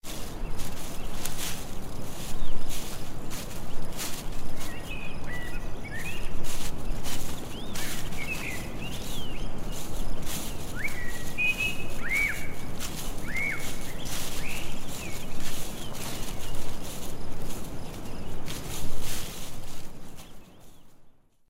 Caminar campo
Sionido de unos pasos caminando por el campo